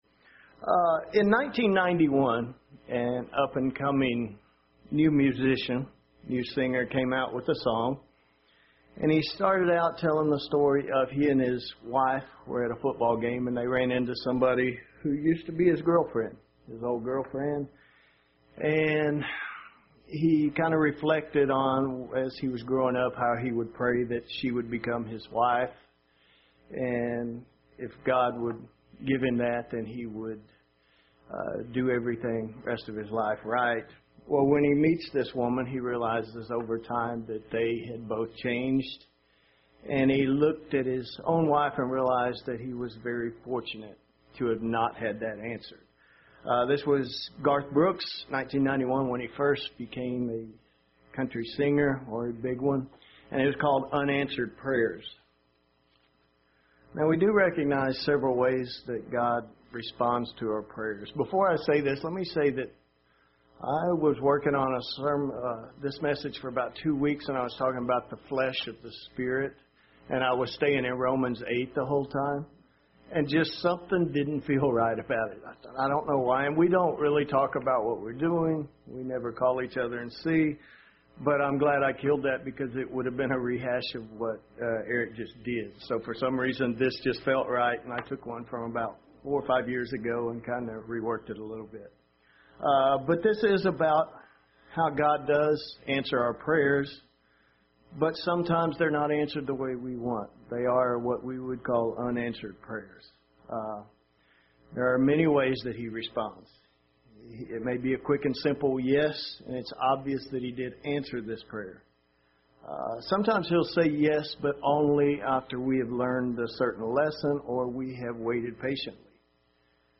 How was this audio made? Given in Murfreesboro, TN